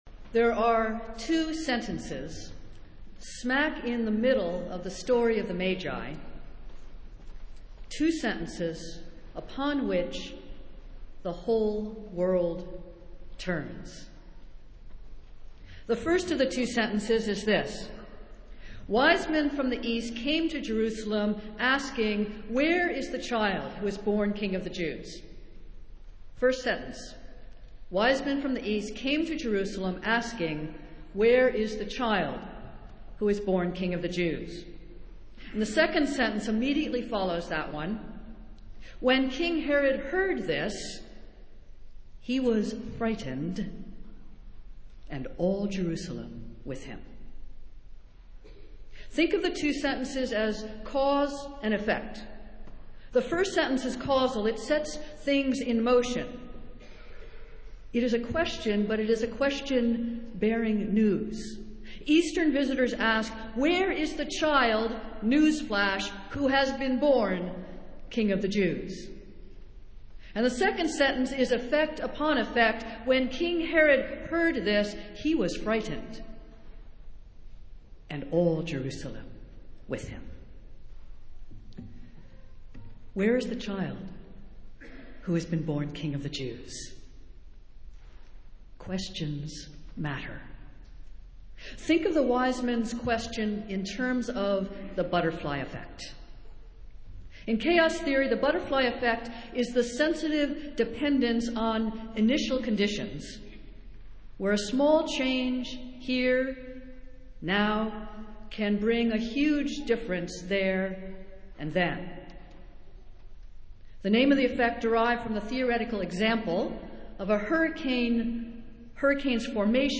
Festival Worship - Three Kings' Sunday